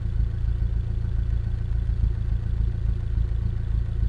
rr3-assets/files/.depot/audio/Vehicles/i4_03/i4_03_idle.wav
i4_03_idle.wav